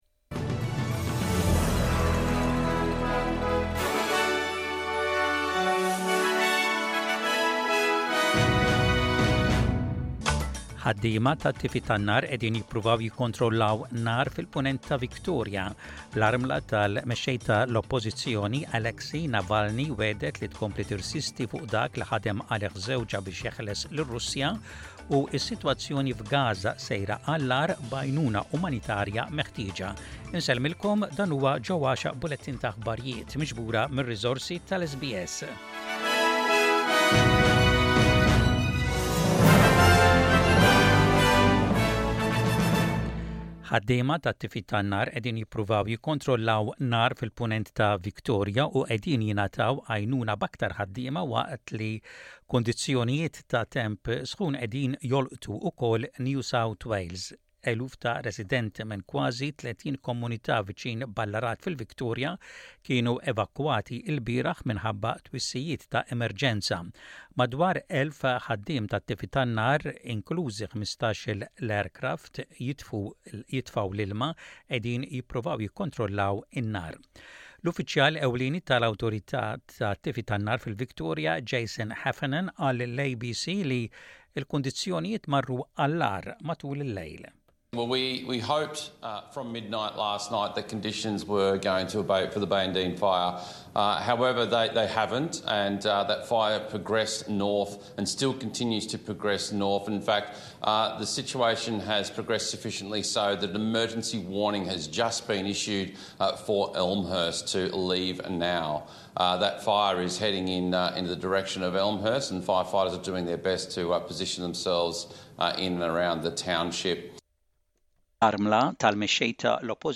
SBS Radio Maltese News